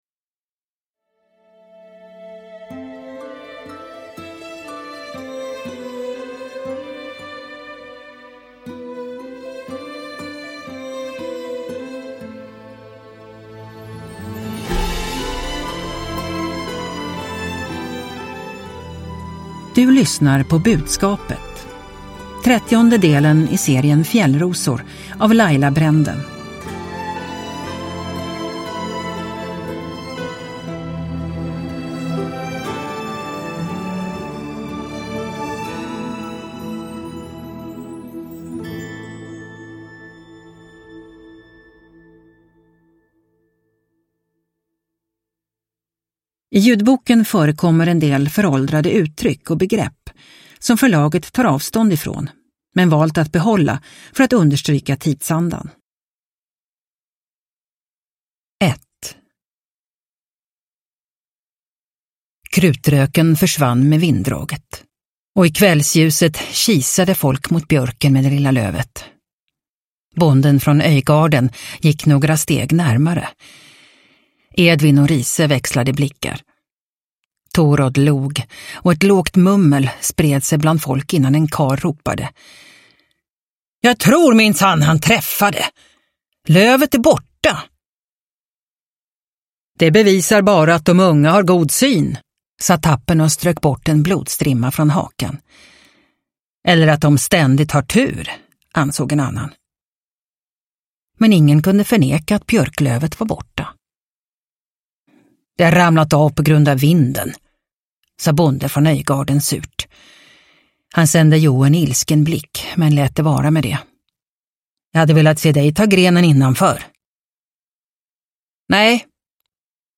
Budskapet – Ljudbok – Laddas ner